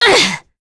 Demia-Vox_Attack3.wav